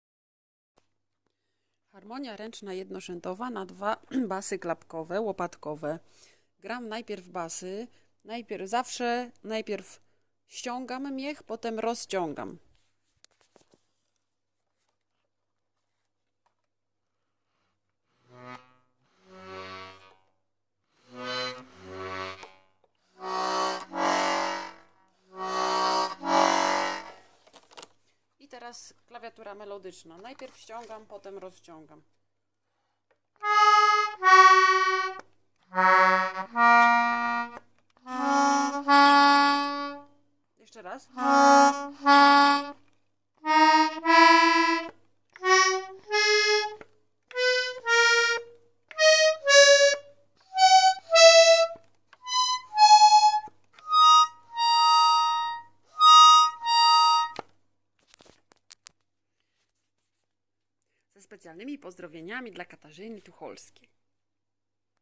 harmonia
harmonia.mp3